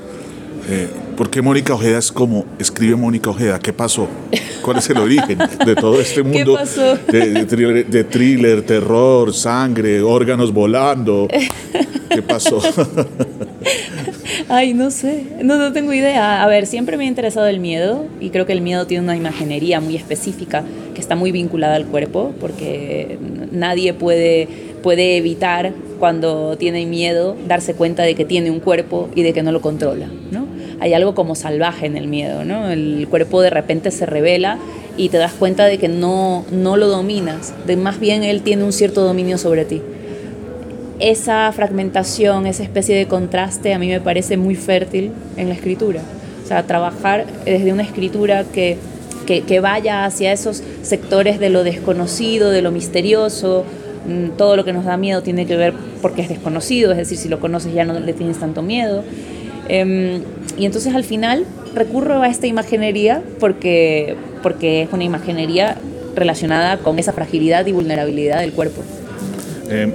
Conversaciones sobre feminismo, deseo y creatividad en esta entrevista.